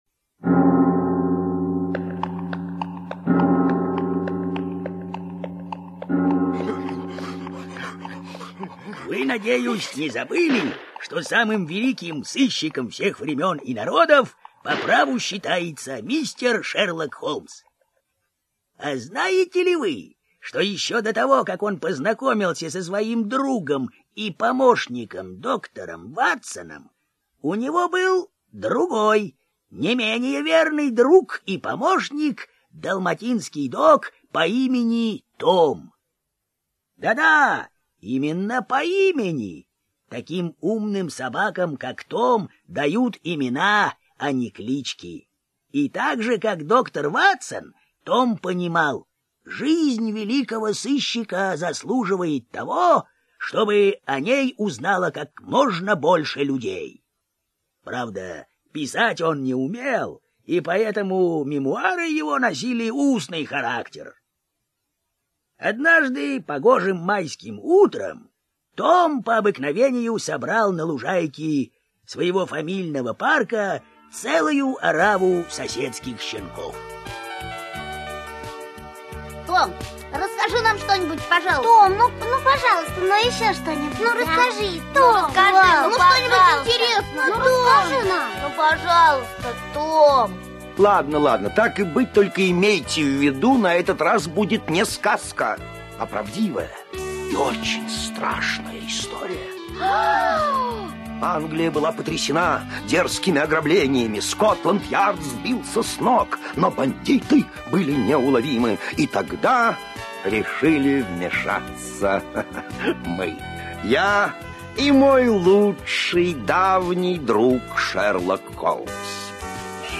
Мы с Шерлоком Холмсом - аудио рассказ Злотникова - слушать онлайн